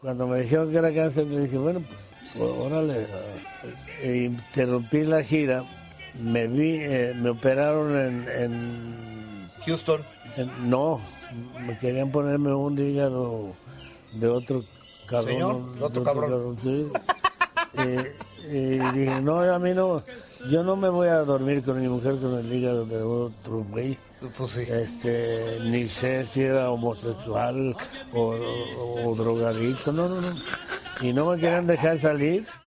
Fernández concedió hace un par de días una entrevista a la cadena mexicana Imagen y habló de su vida.
Rechazó el hígado porque no sabía si el donante era “homosexual o drogadicto”, comentario que parece hacer mucha gracia al periodista.